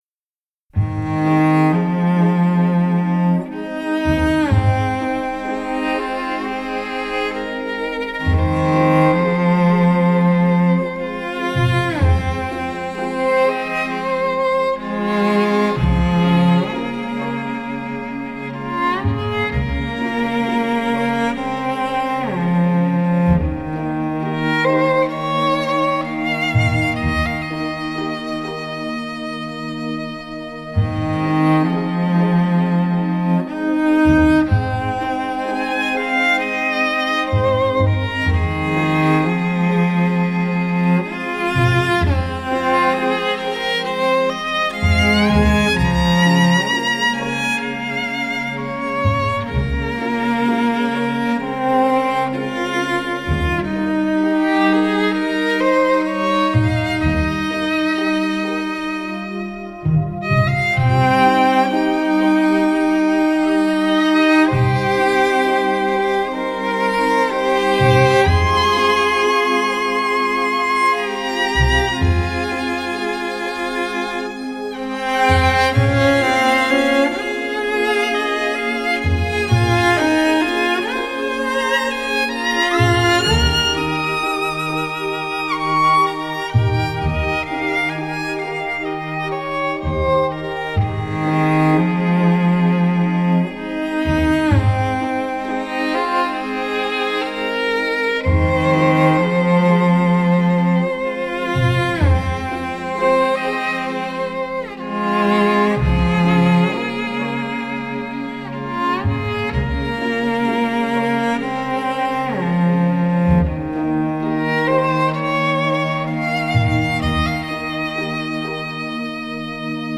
长笛、钢琴
吉他
大提琴
小提琴